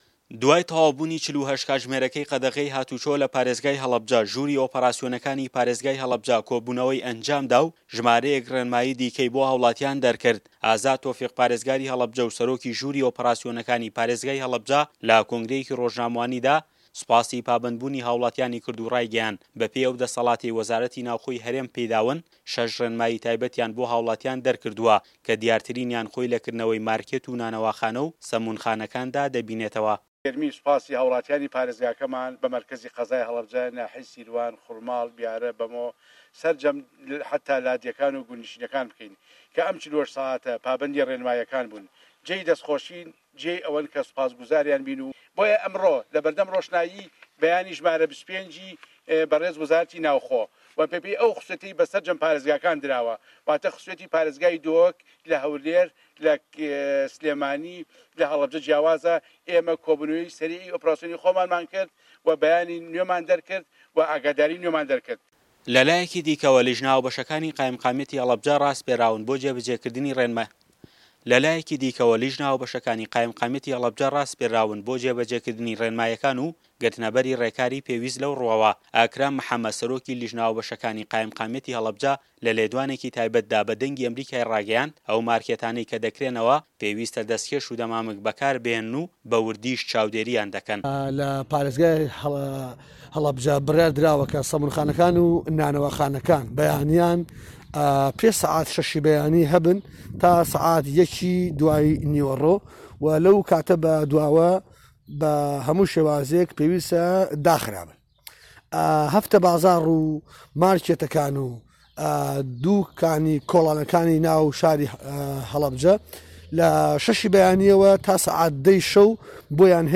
ارێزگاری هه‌ڵه‌بجه‌ له‌ كۆنگره‌یه‌كی ڕۆژنامه‌وانی شه‌ش ڕێنمایی تایبه‌ت بۆ هاوڵاتیان ڕادەگەیەنێت و ه‌ دیارترینیان كردنه‌وه‌ی ماركێت و نانه‌واخانه‌ و سه‌موون خانه‌كانن.‌